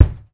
04B KICK  -R.wav